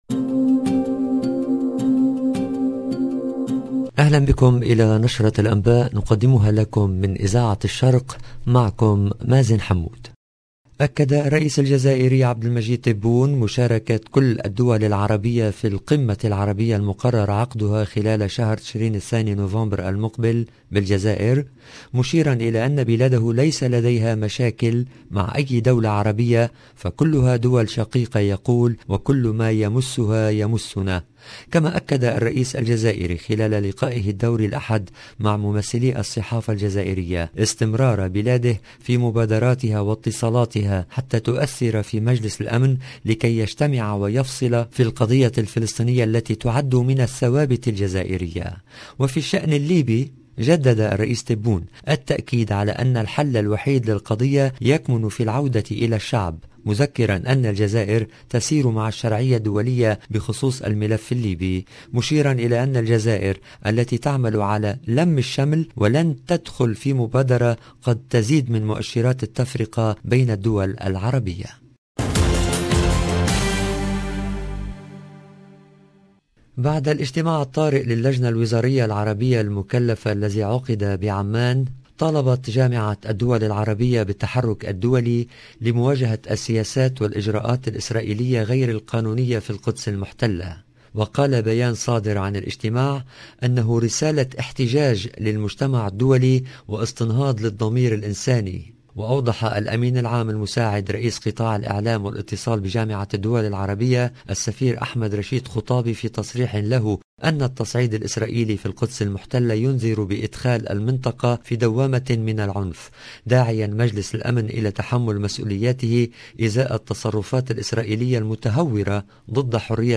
LE JOURNAL DU SOIR EN LANGUE ARABE DU 25/04/22